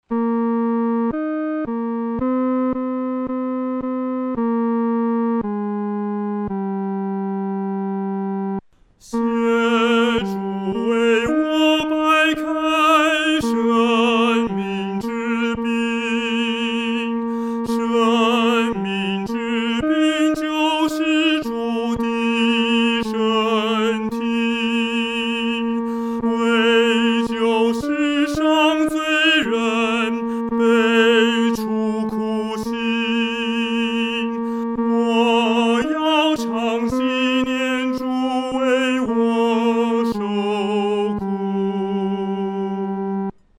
独唱（第三声）
求主掰开生命之饼-独唱（第三声）.mp3